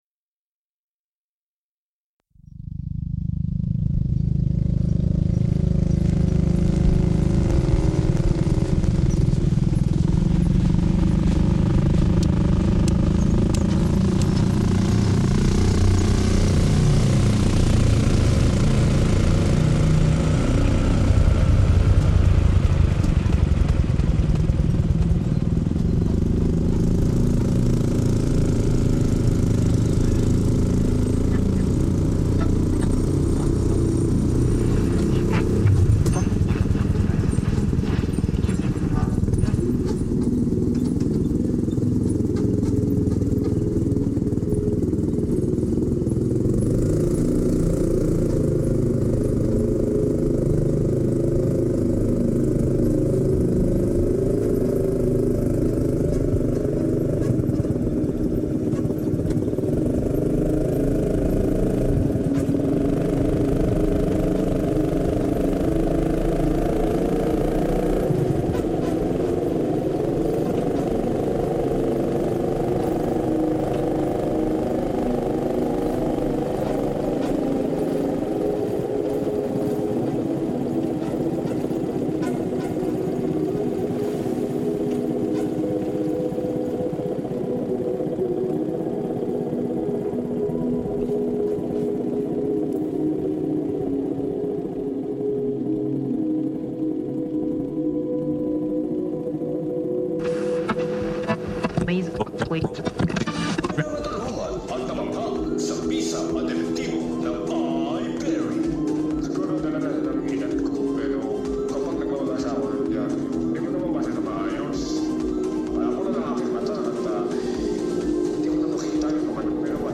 Cabusao tricycle ride, Philippines